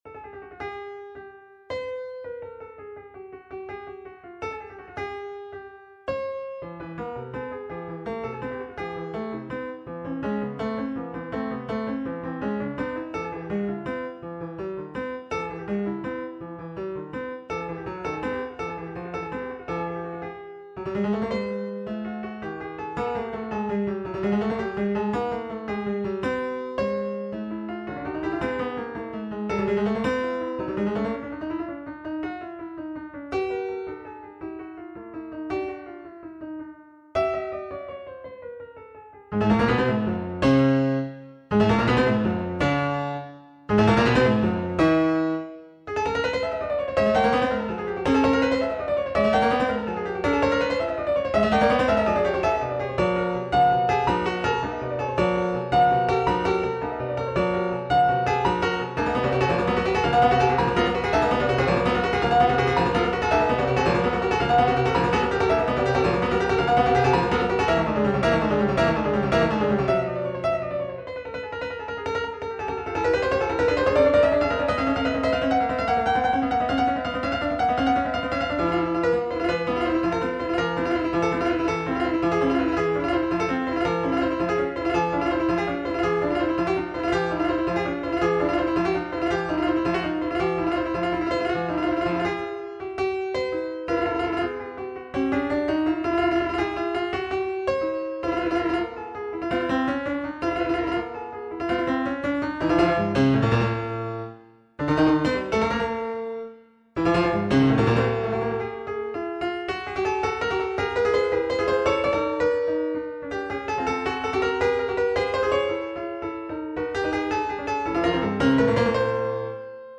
Looking for any comments regarding mastering, finishing touches, etc. I'm using the Steinway D Prelude model.
The piece was then transformed into a piece for 2 12et keyboards which can play quartertones.
acidbach1-acoustic.mp3